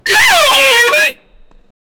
man biting a hdmi cable and screaming with joy for his sucess
man-biting-a-hdmi-cable-vhrx2f75.wav